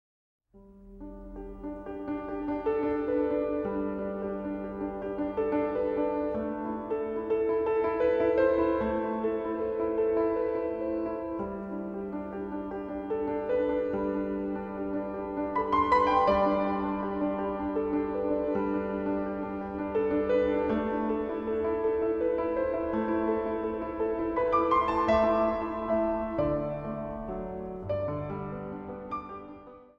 Instrumentaal | Orgel
Instrumentaal | Piano